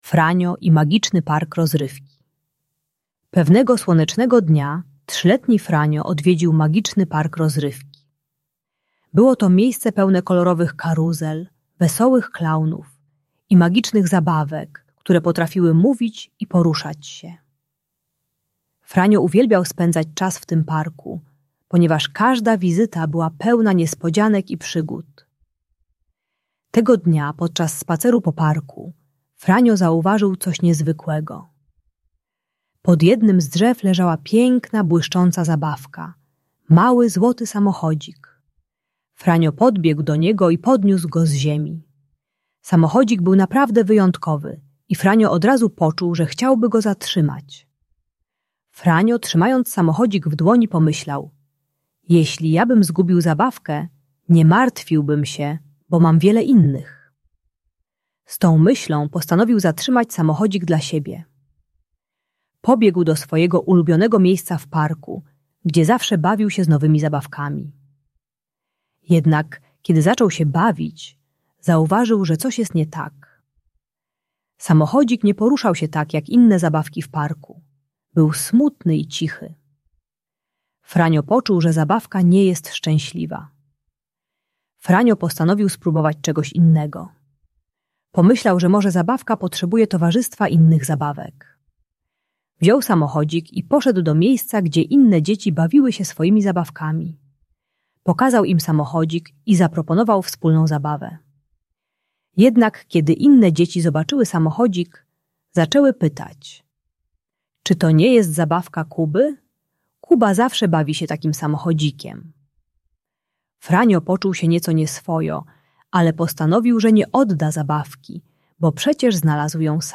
Franio i Magiczny Park Rozrywki - Niepokojące zachowania | Audiobajka
Audiobajka o uczciwości dla maluchów.